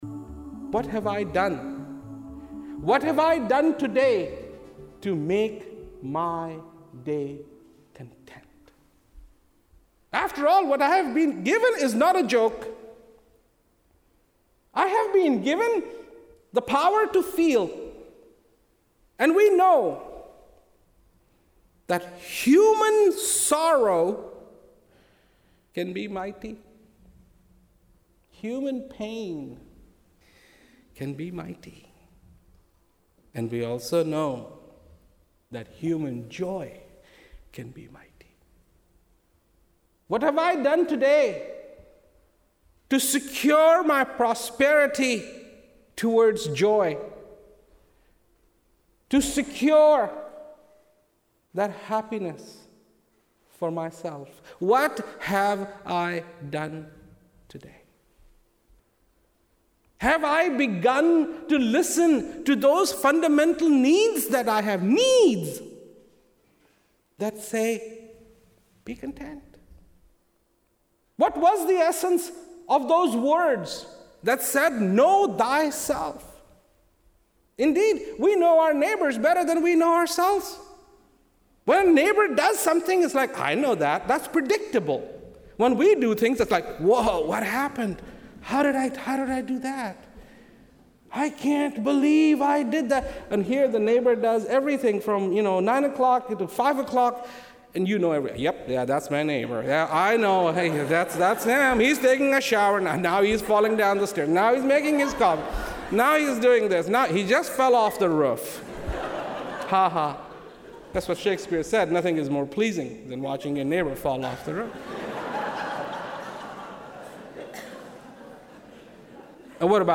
Transcripts of Rawat's speeches cannot do justice to Rawat's astonishing delivery, they have to be heard to be appreciated. Mp3 copies of these excerpts are recorded at high quality (256Kbps) to ensure no whispered nuance or frenzied climax is missed.